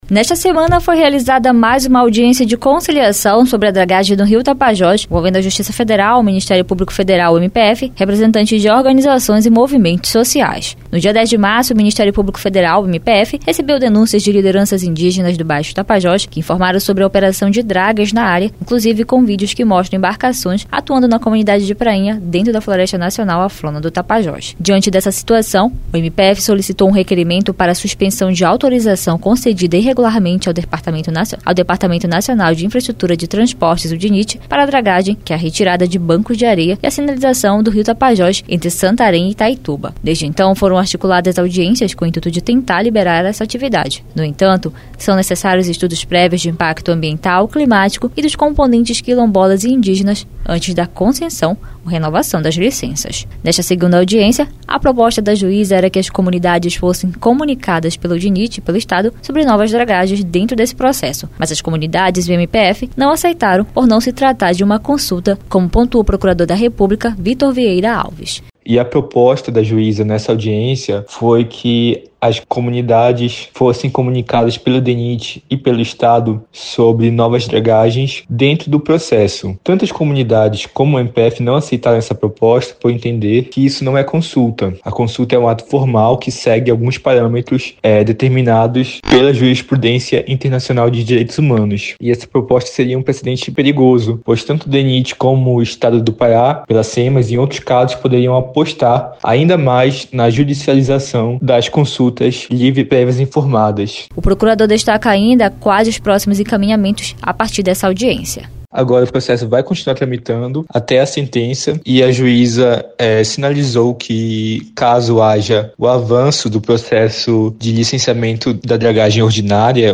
Reportagem